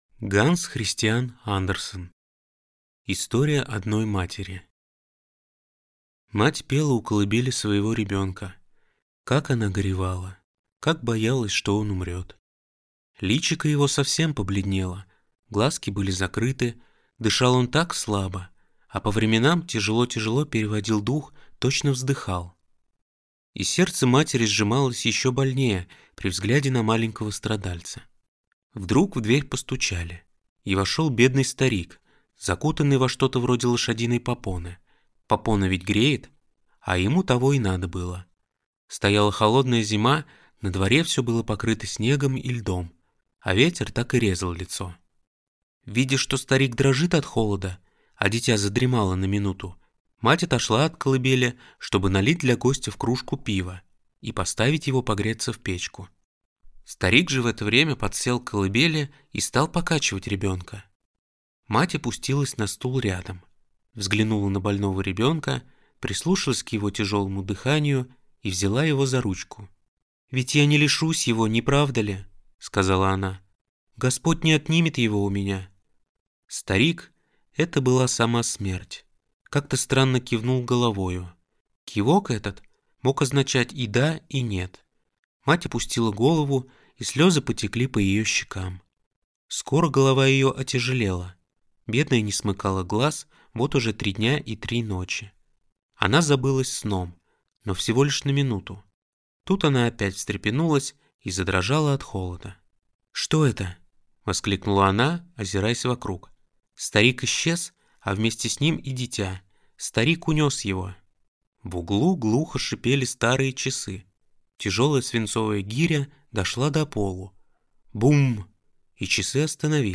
Аудиокнига История одной матери | Библиотека аудиокниг